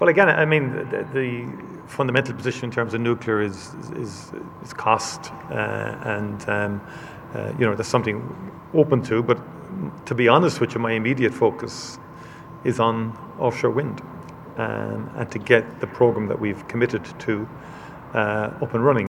Taoiseach Michael Martin says changing the policy could be looked at, but he has other priorities…………….